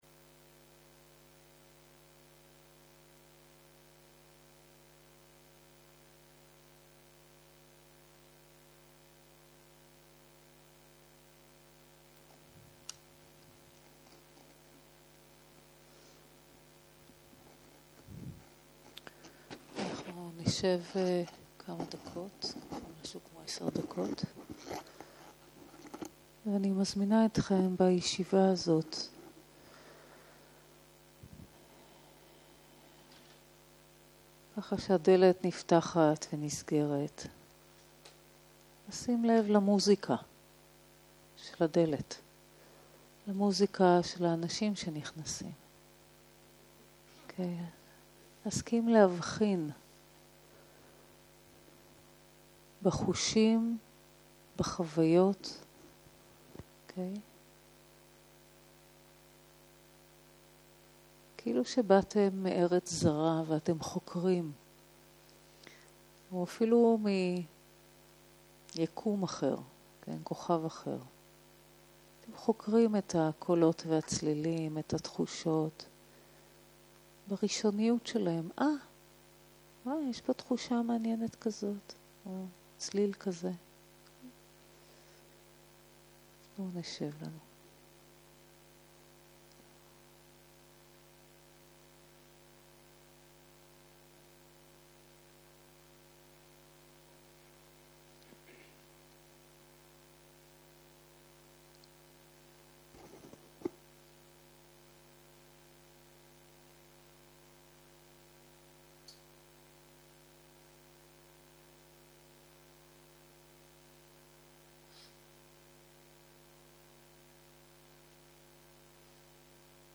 Dharma Talks